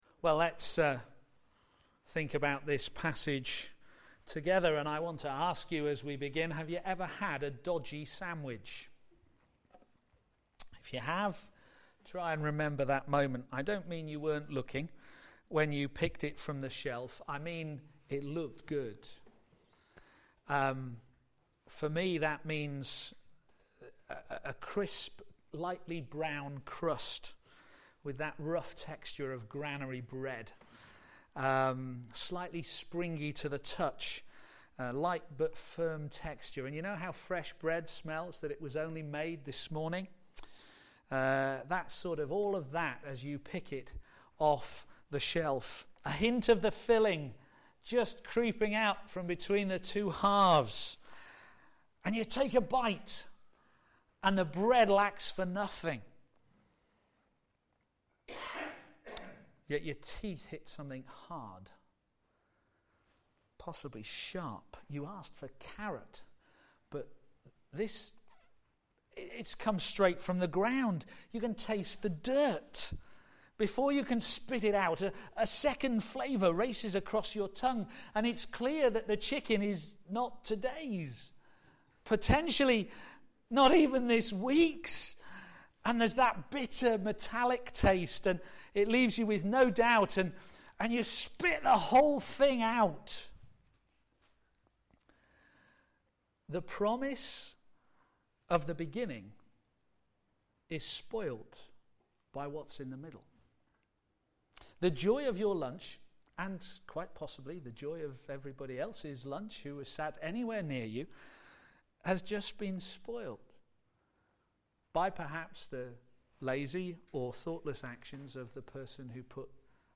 p.m. Service
Series: Working Together to Advance the Gospel Theme: We are the Temple of God; avoid a worldly mindset Sermon